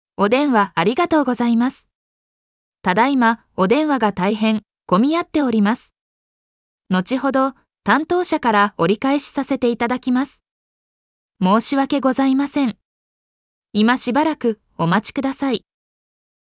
■折り返しガイダンス